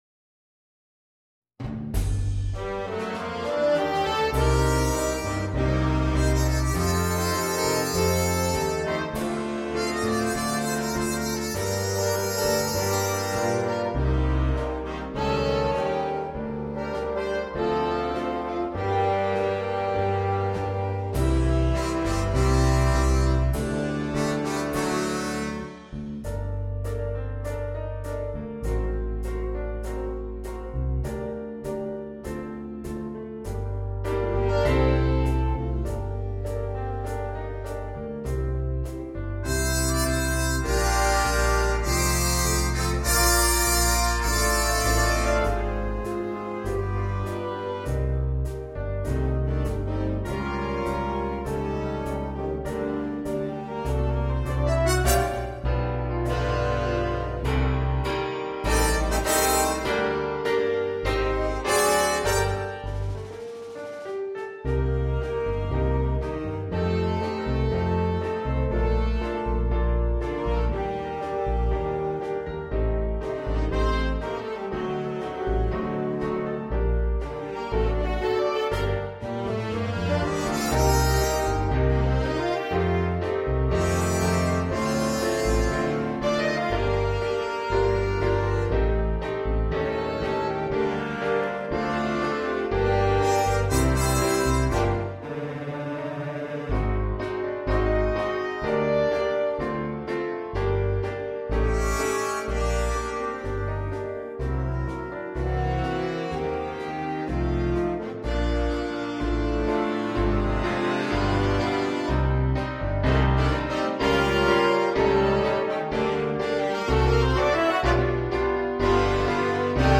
на смешанный состав.